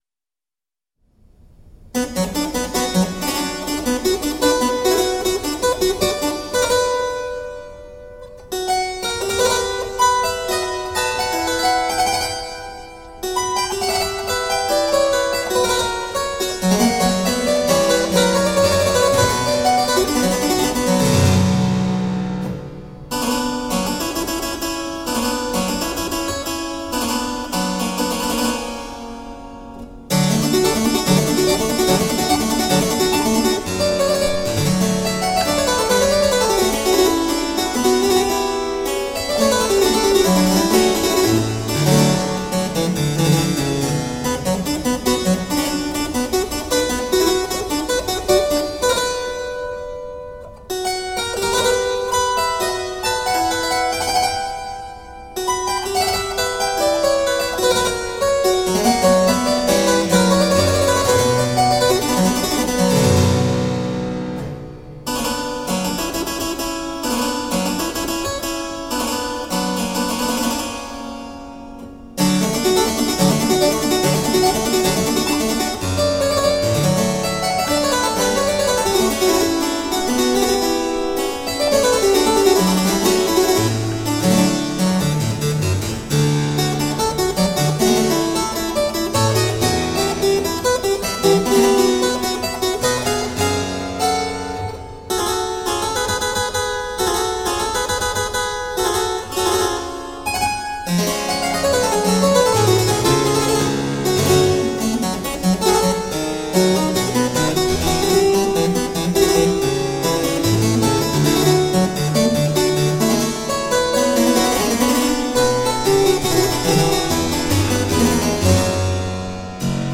Crisp, dynamic harpsichord.